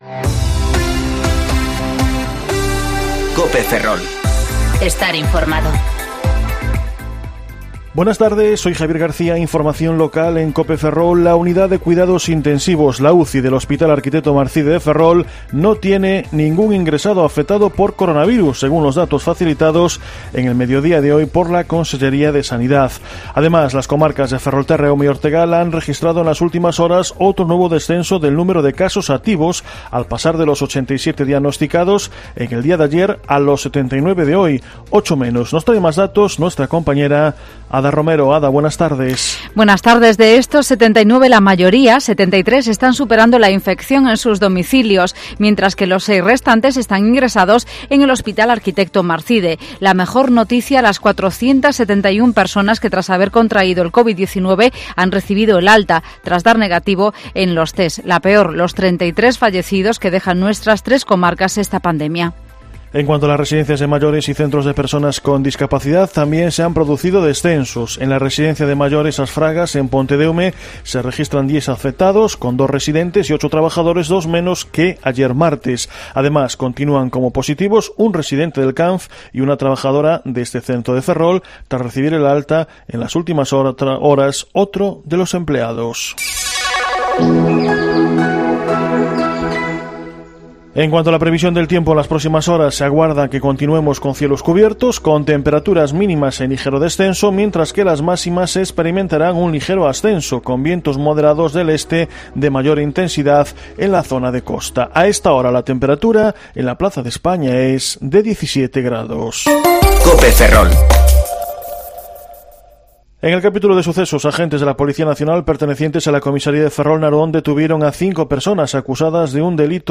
Informativo Mediodía COPE Ferrol 13/05/2020 ( De 14,20 a 14,30 horas)